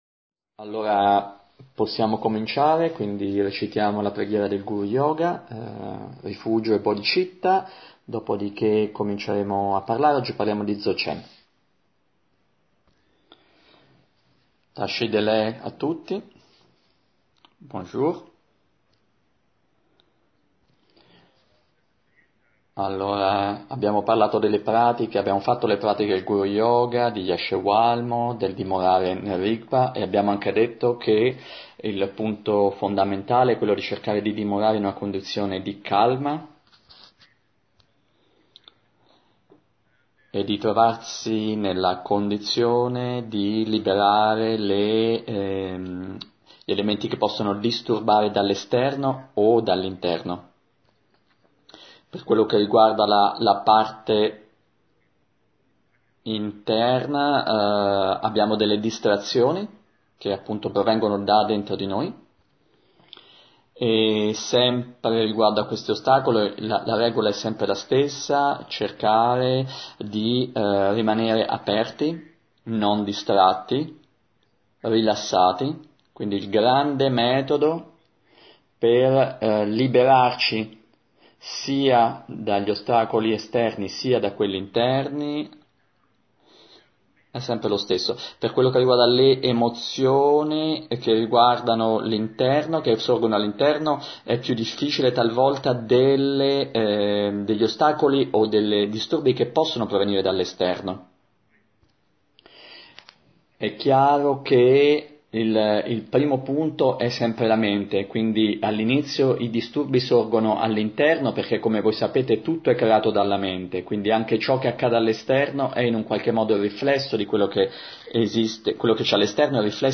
Insegnamento orale
Traduzione simultanea